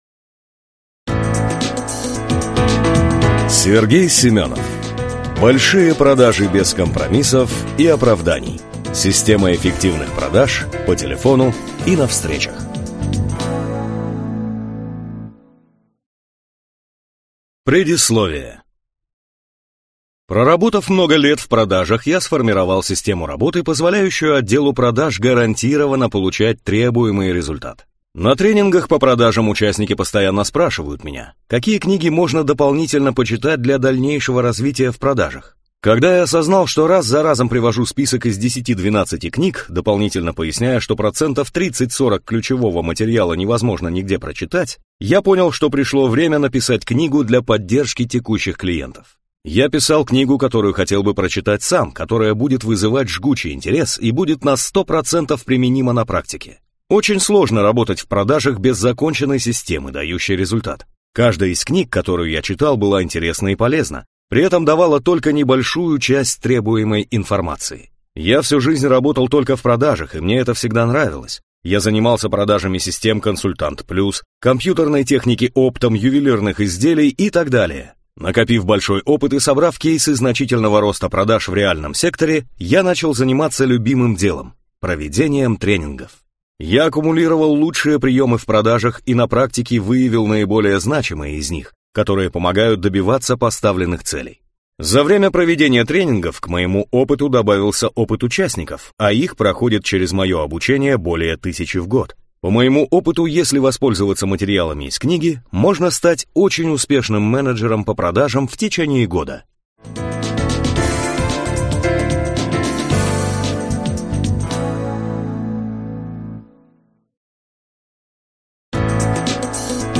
Аудиокнига Большие продажи без компромиссов и оправданий: Система эффективных продаж по телефону и на встречах | Библиотека аудиокниг